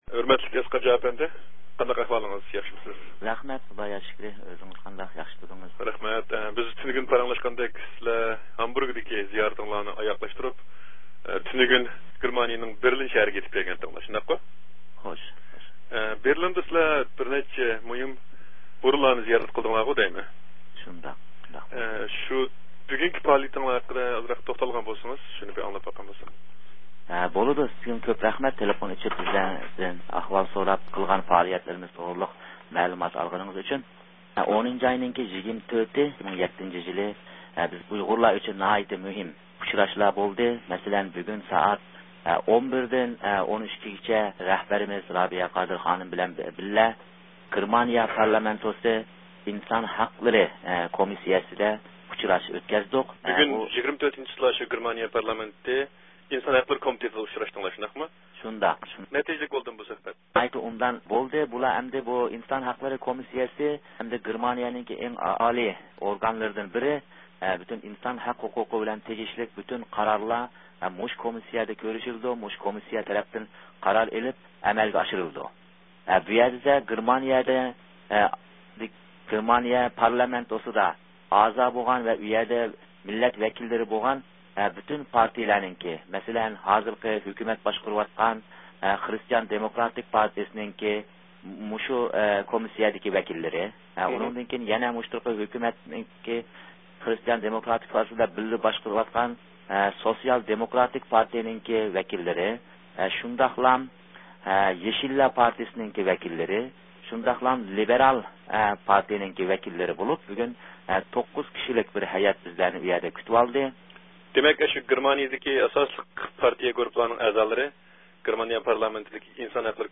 بېرلىندىكى پائالىيەتلەر توغرىسىدىكى سۆھبەت خاتىرىسىنى دىققىتىڭلارغا سۇنىمىز.